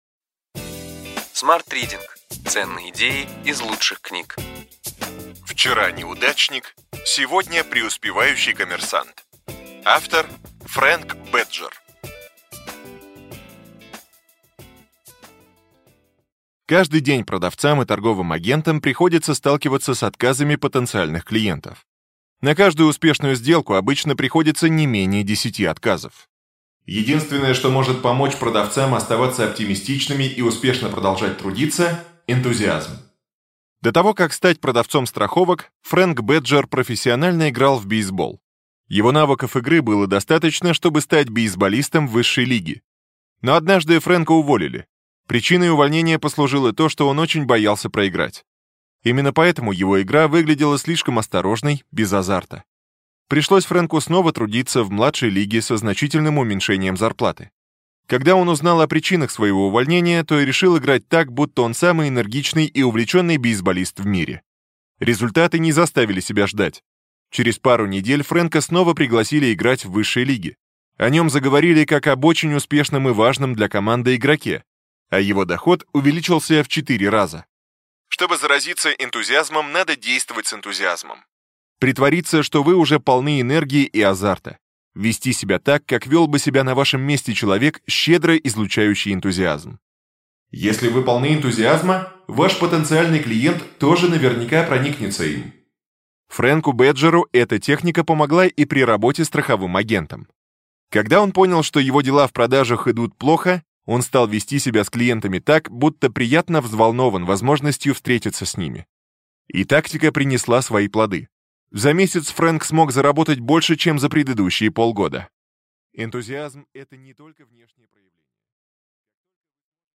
Аудиокнига Ключевые идеи книги: Вчера неудачник – сегодня преуспевающий коммерсант.